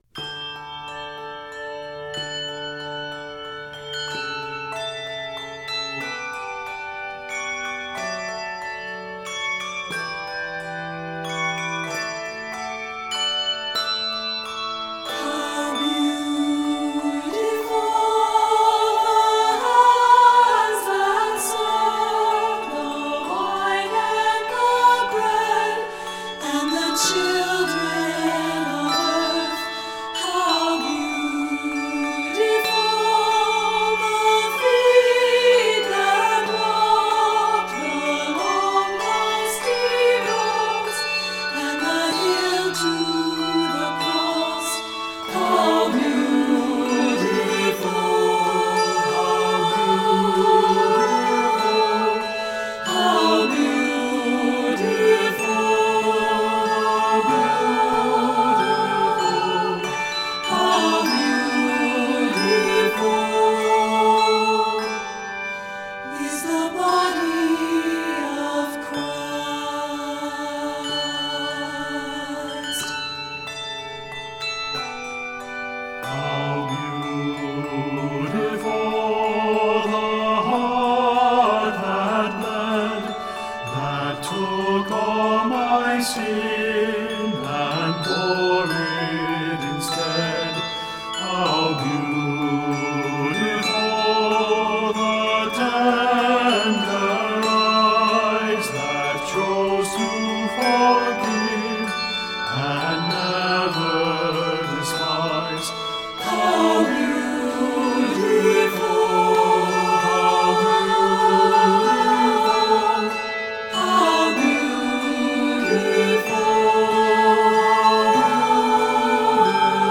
Set in D Major and Eb Major, measures total 100.
Octaves: 3-5